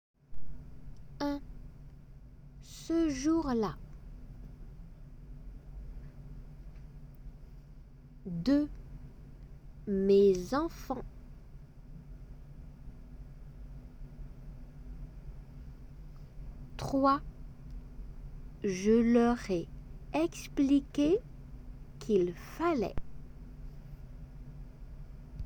仏検2級　デイクテ　練習 7 音声